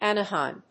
/ˈænʌˌhaɪm(米国英語)/
フリガナアナハイム